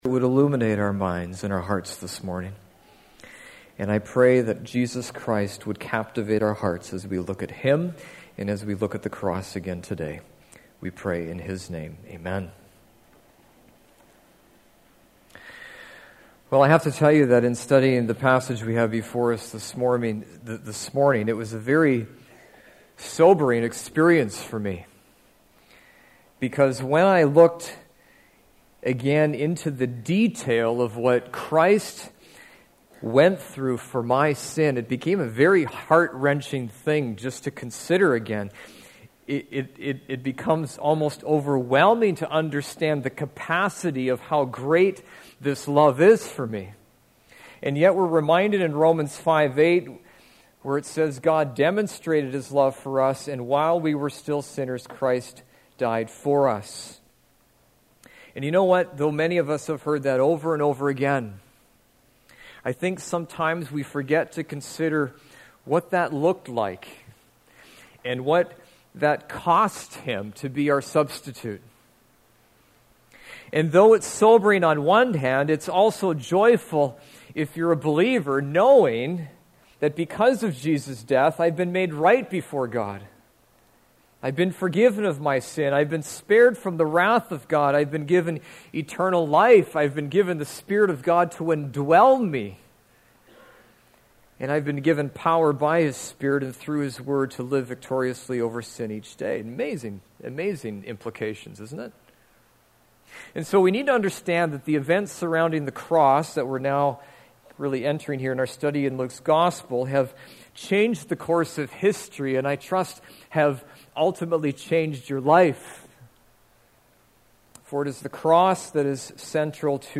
Sermons | Rothbury Community Church